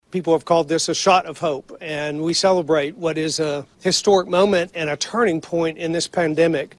Governor Bill Lee Is Excited….